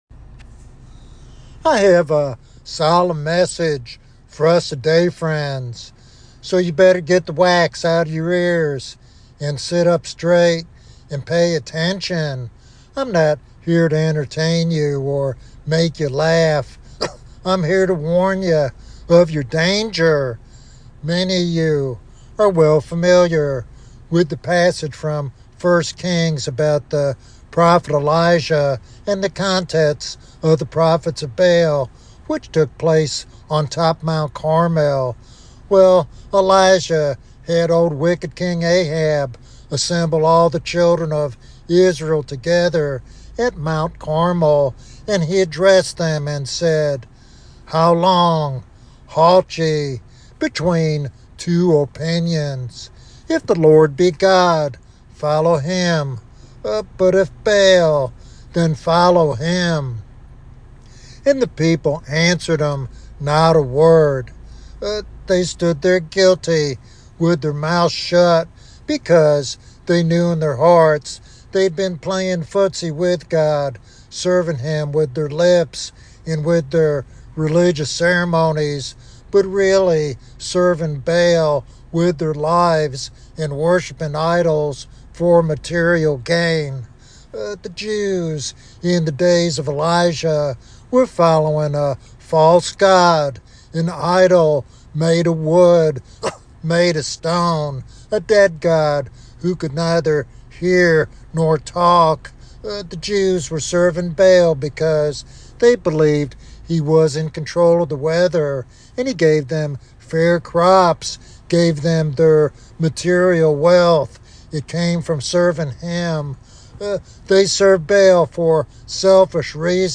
This sermon calls for serious repentance, obedience, and a wholehearted commitment to Christ.